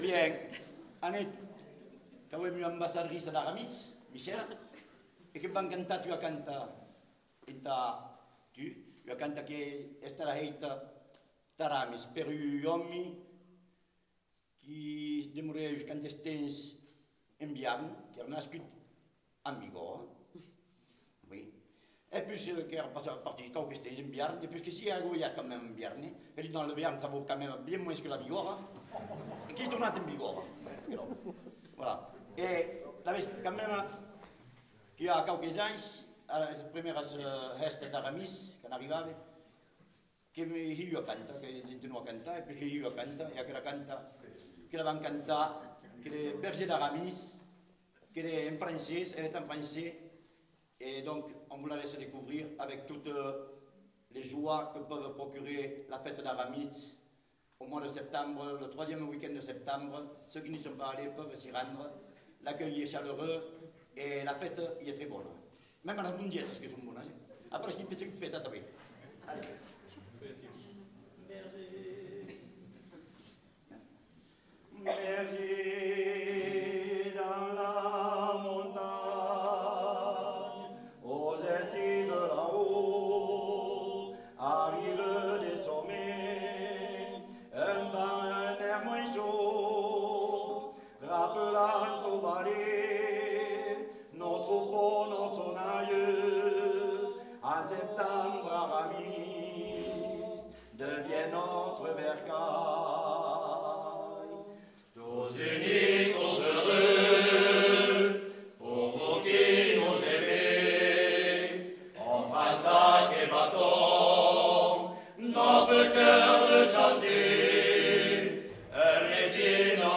Eths amassats de Bigòrra (ensemble vocal)
Aire culturelle : Bigorre
Lieu : Ayros-Arbouix
Genre : chant
Type de voix : voix d'homme
Production du son : chanté
Descripteurs : polyphonie